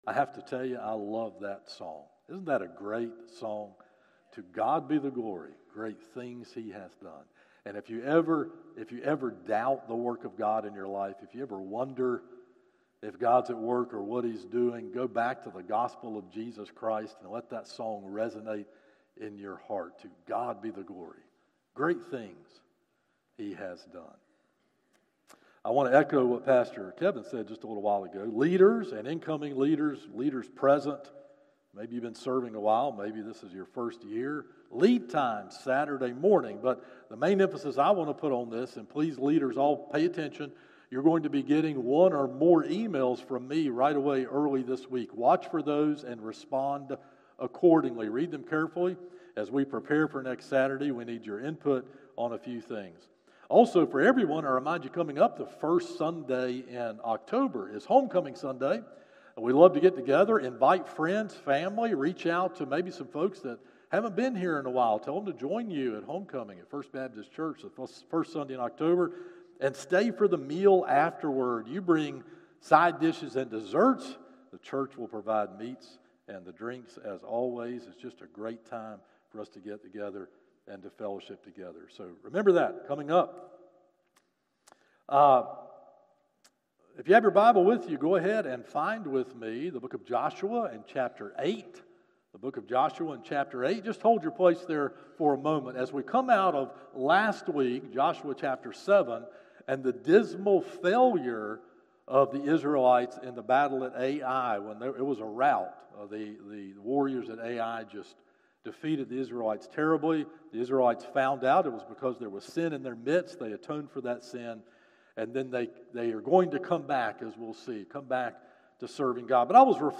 Morning Worship - 11am Passage